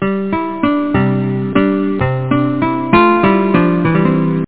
SoftMelody.mp3